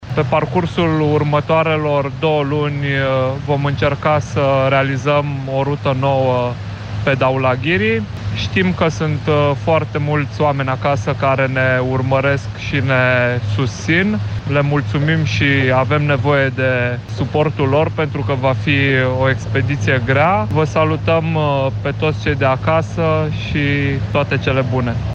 Alpinistul Horia Colibășanu transmite un mesaj din noua expediție din Himalaya